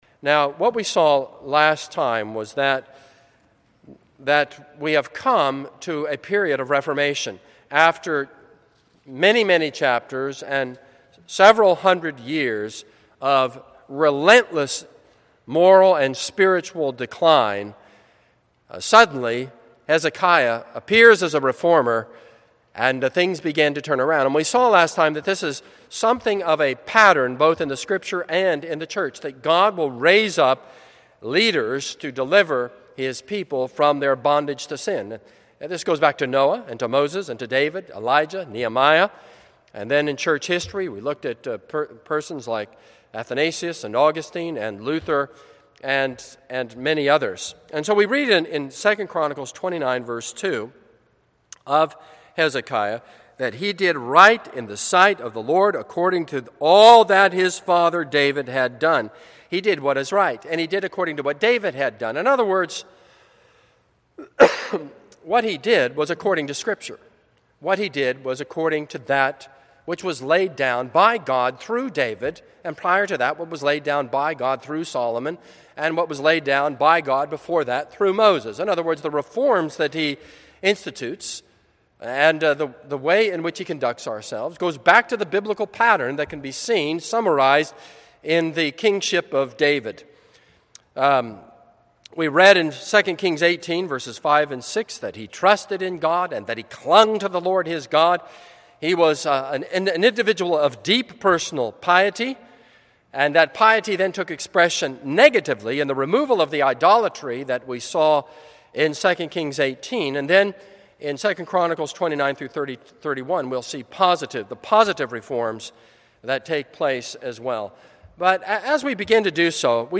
This is a sermon on 2 Kings 18:1-8.